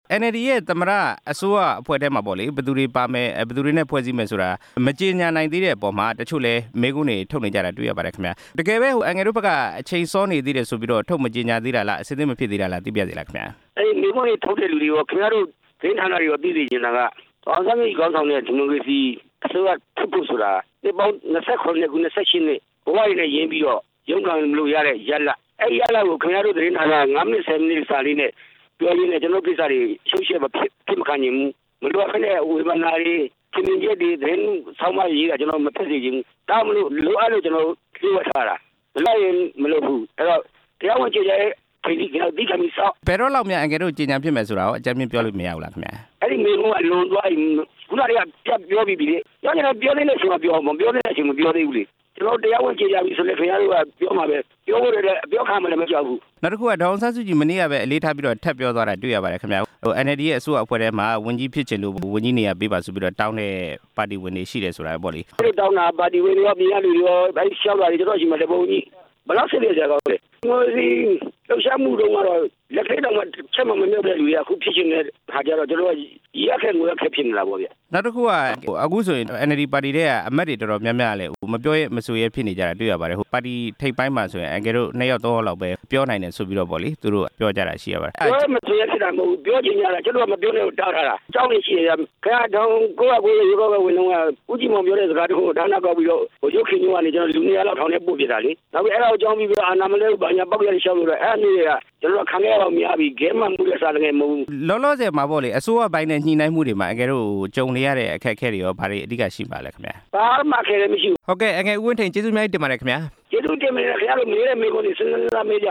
NLD သမ္မတလောင်းနဲ့ အစိုးရအဖွဲ့စာရင်း ထုတ်ပြန်ကြေညာရေးအစီအစဉ် ဦးဝင်းထိန်နဲ့ မေးမြန်းချက်